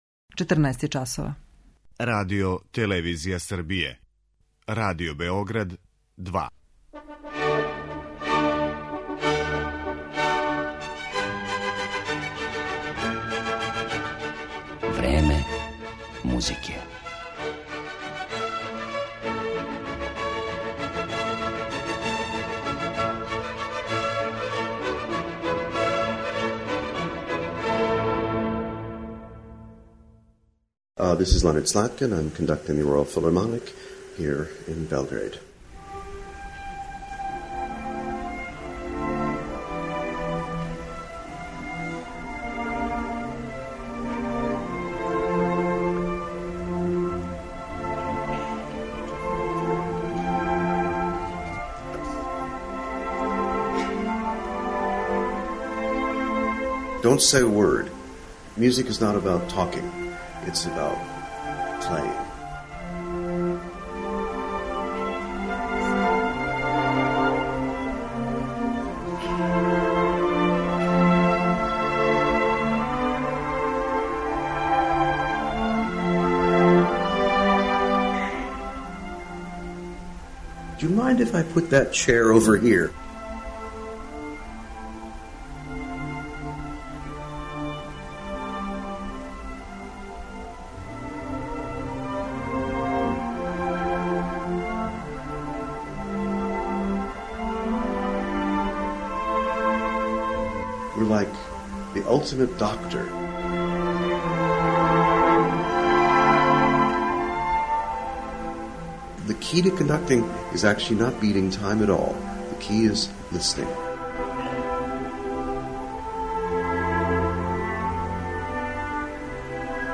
2007. године је са Краљевским филхармонијским оркестром из Лондона гостовао и у Београду и том приликом снимљен је и интервју са овим великим америчким музичарем, који ћете моћи да чујете у емисији.